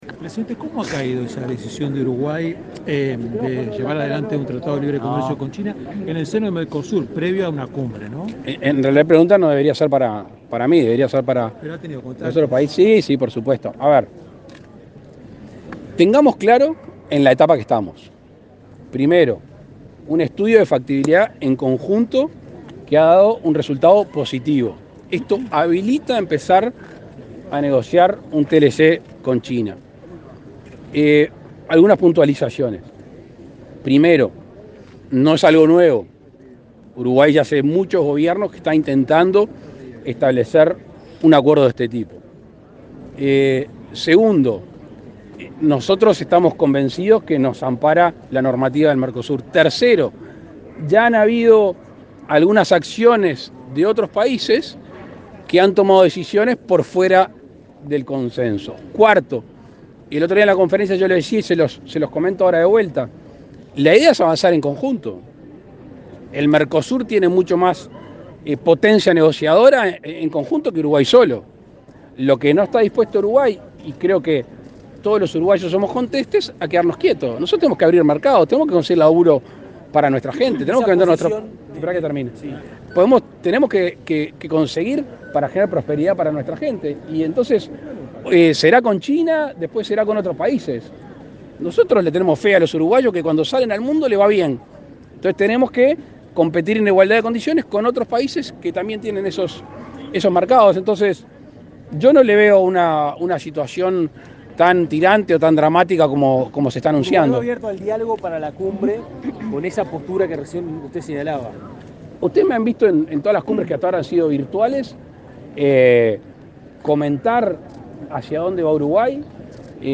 Declaraciones del presidente Lacalle Pou a la prensa
El presidente Luis Lacalle Pou encabezó el acto de celebración del 192.° aniversario de la Jura de la Constitución. Luego, dialogó con la prensa.